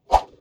Close Combat Swing Sound 66.wav